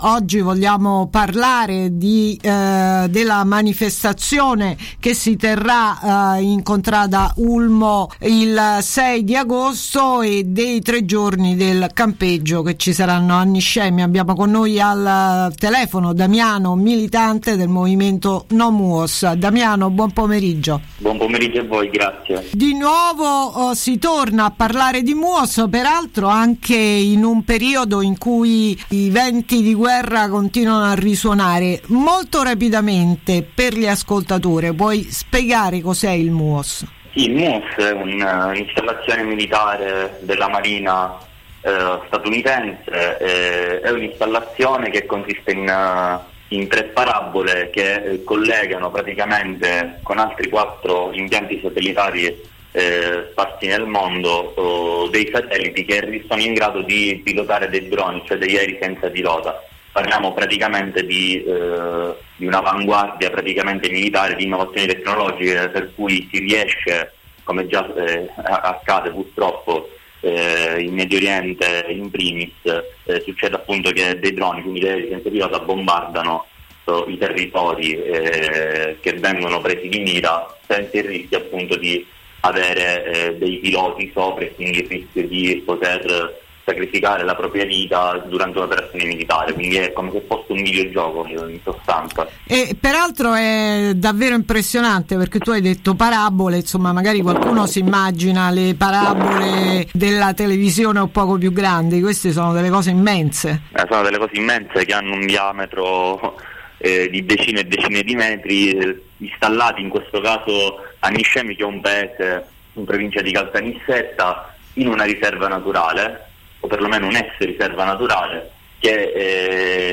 Intervista